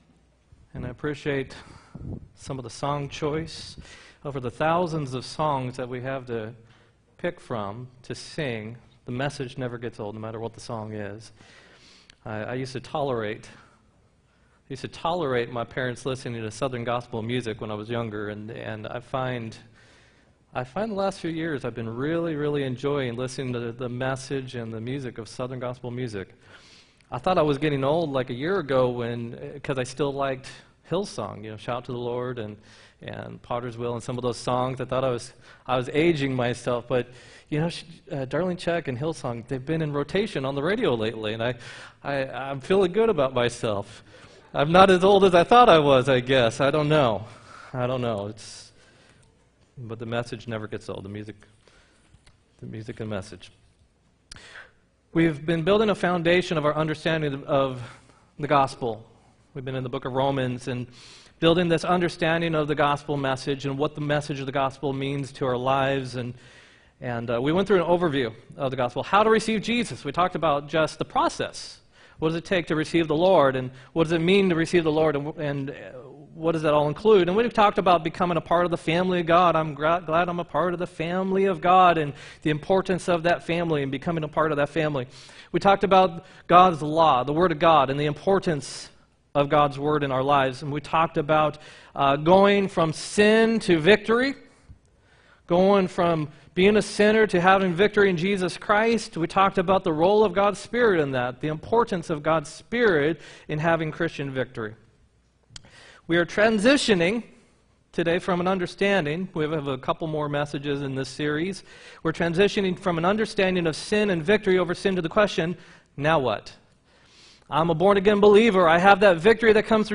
8-18-18 sermon